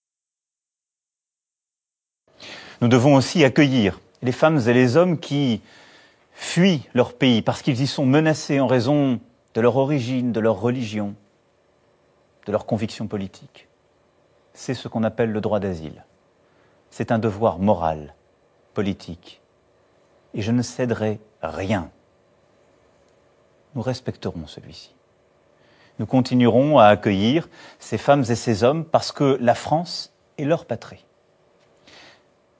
Emmanuel Macron, premiers voeux aux Français le 31 décembre 2017.